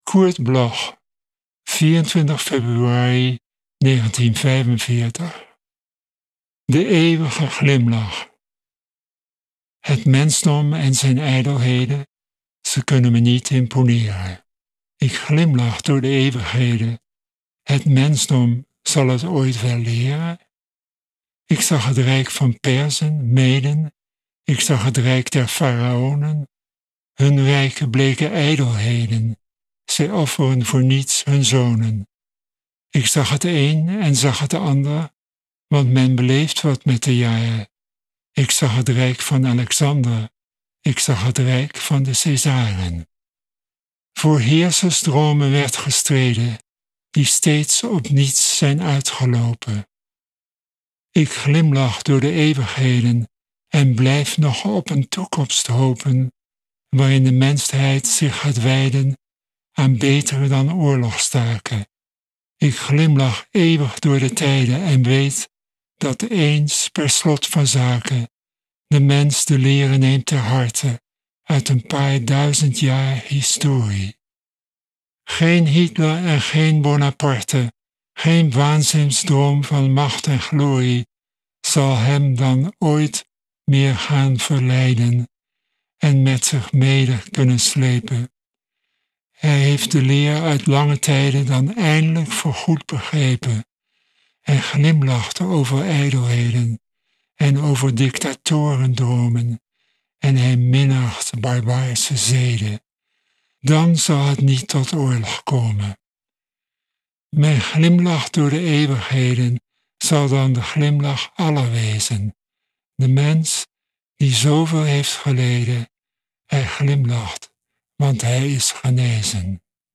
voorgedragen door Carel Struycken